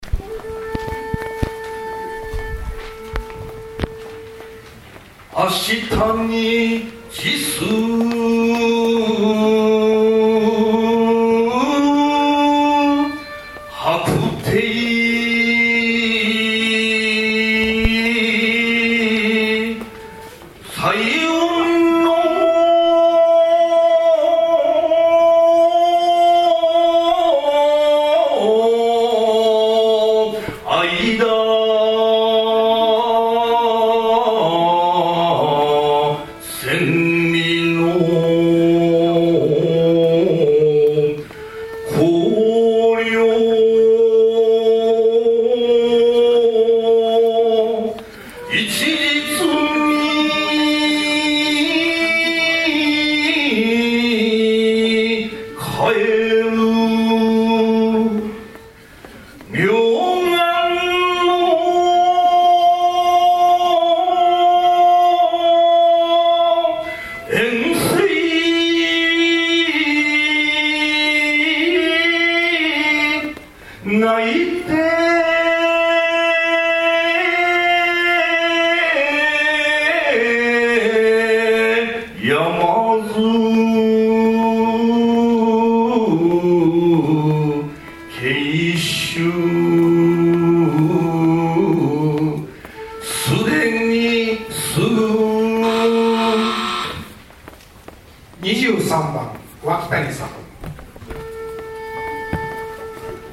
２０１２年８月２６日 日本コロムビア全国吟詠コンクール（神戸地区予選） 『白帝城』 二本
この大会は生の尺八演奏による伴奏で、２分ほどでブザーがなり終わります。
音程のずれは前回よりも少しはましだとは思うのですが・・・。